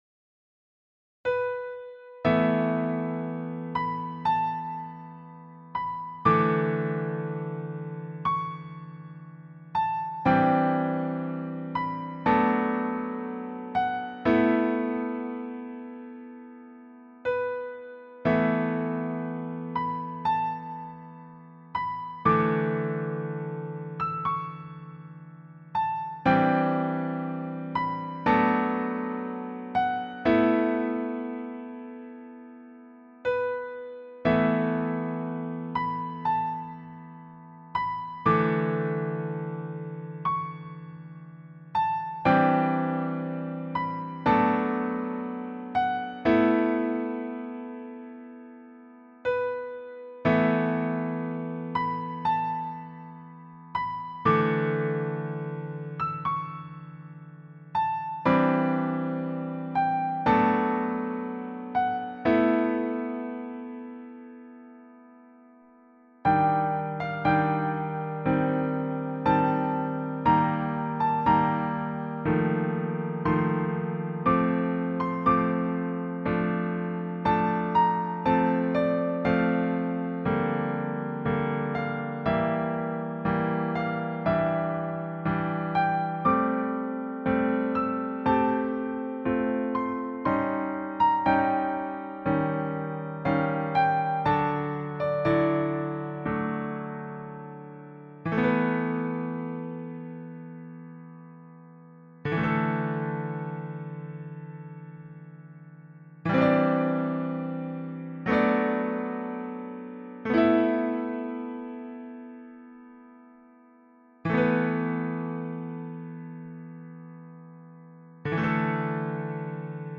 A remix